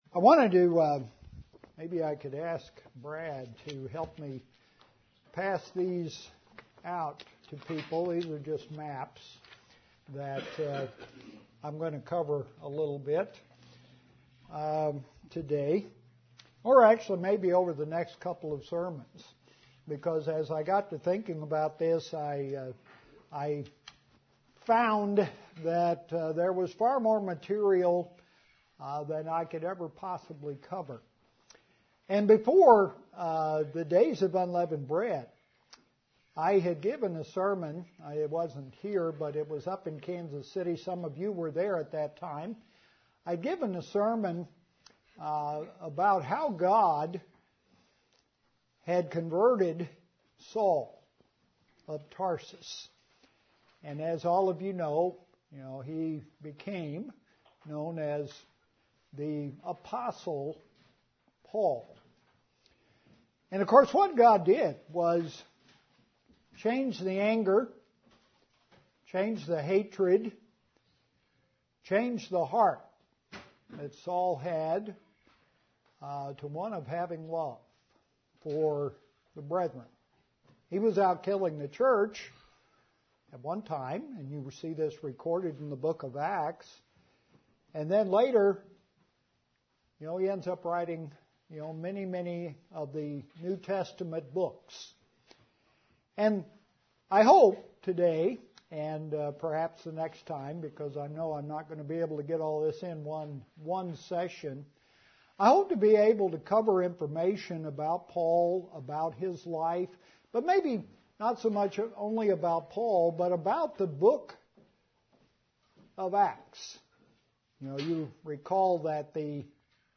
Part 1 of sermon series on the Book of Acts